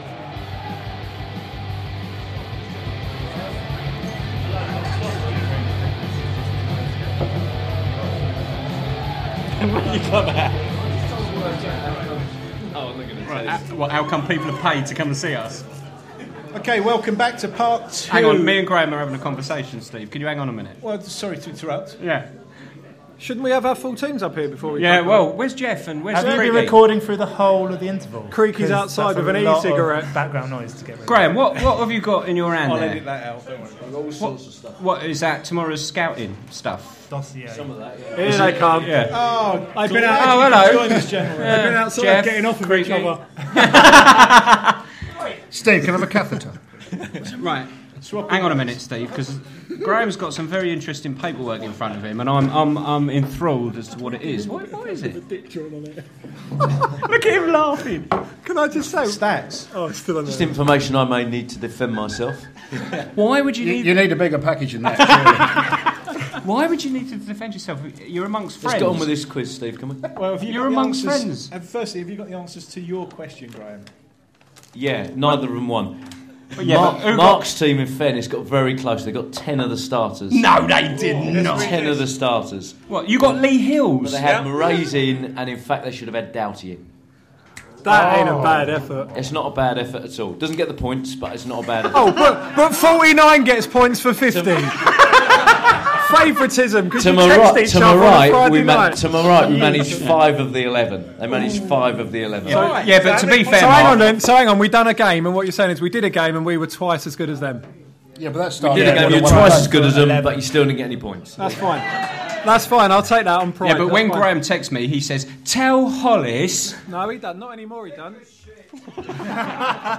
The second of a three-part instalment that takes you right back to A Load Of Old Toffee Live special – recorded down at Stripes in 2014 | BoroGuide
WARNING: Contains strong language